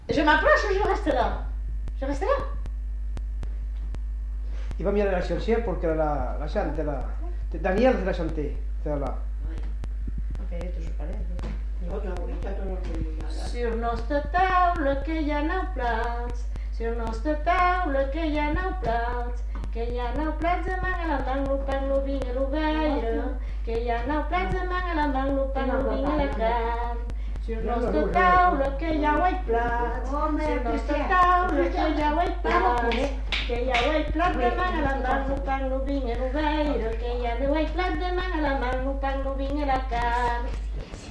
Genre : chant
Effectif : 1
Type de voix : voix d'homme
Production du son : chanté
Classification : chansons de neuf